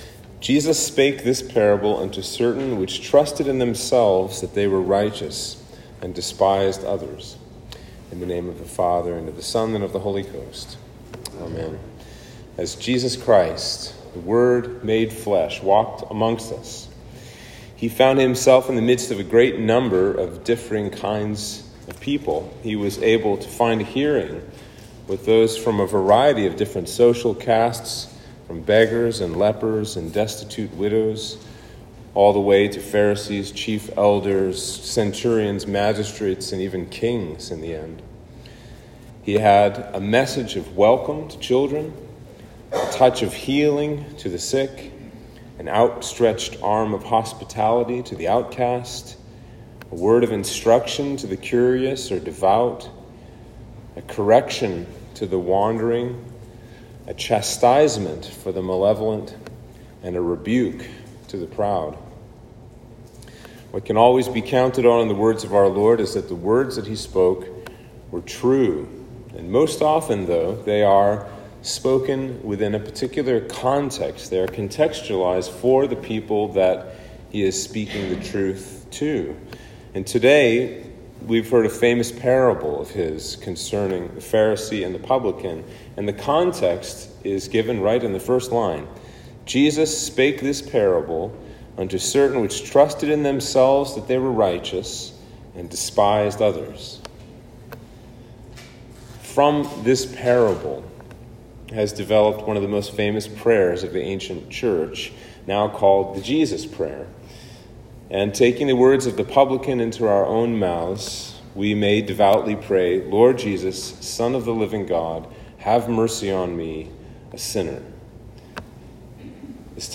Sermon for Trinity 11